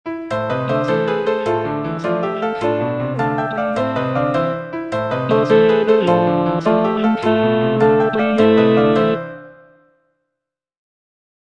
G. BIZET - CHOIRS FROM "CARMEN" Passez davant (bass II) (Voice with metronome) Ads stop: auto-stop